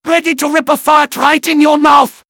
medic_autochargeready01.mp3